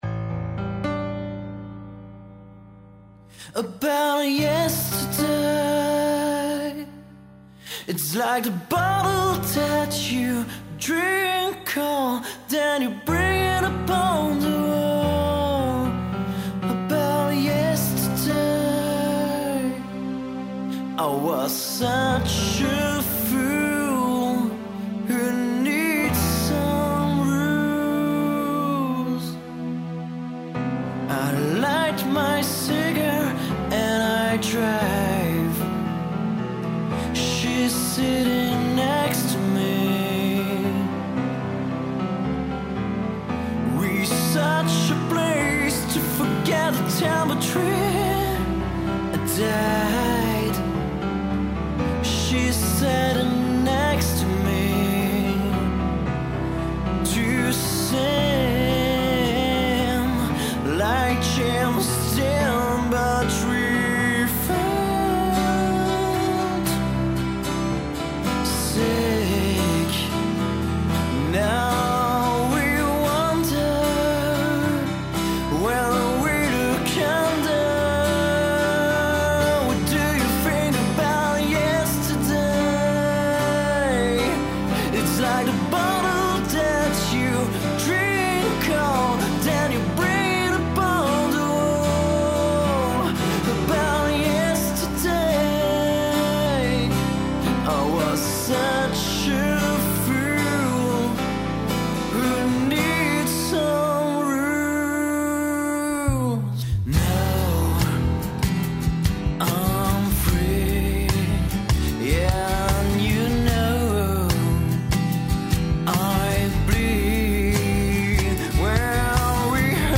Lead guitar & back vocals
Rythmic guitar
Bass & back vocals
Drums